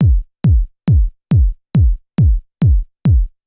BD        -R.wav